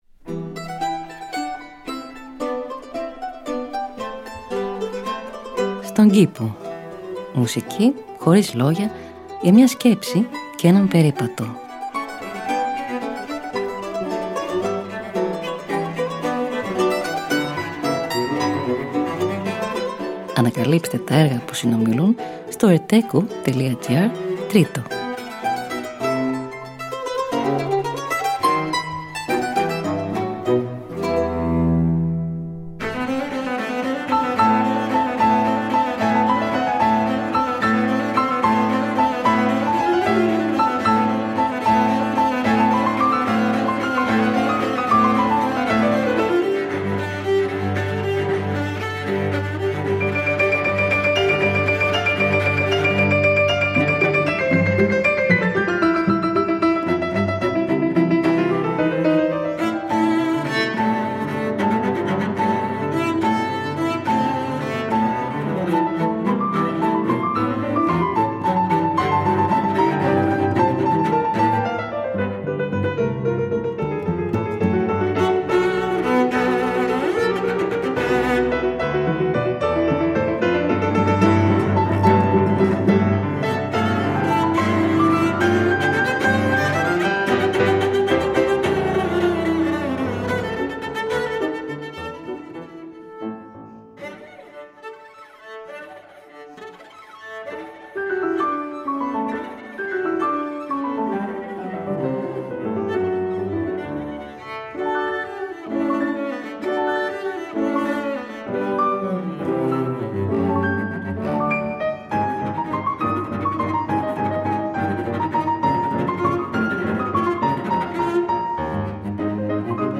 Μουσική Χωρίς Λόγια για μια Σκέψη και έναν Περίπατο.
Allegro – Arrange for mandolin and continuo: Avi Avital